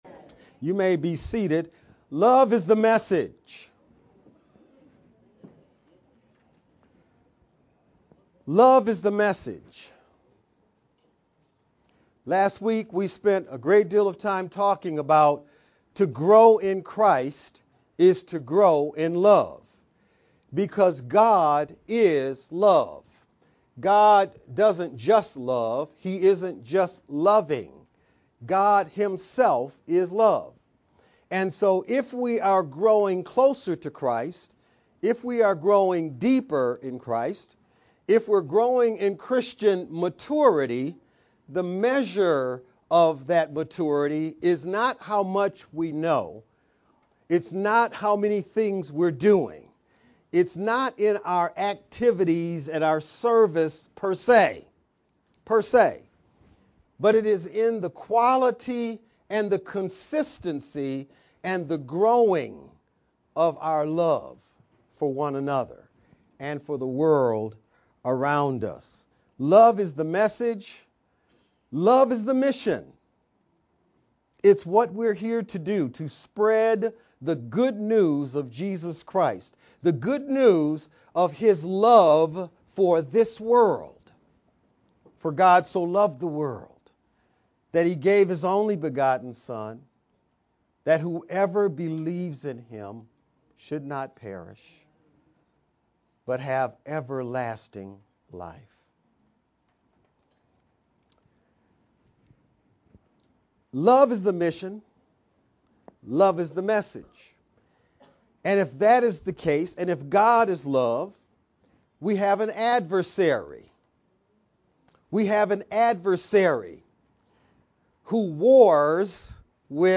VBCC-Service-Oct-20th-edited-sermon-only_Converted-CD.mp3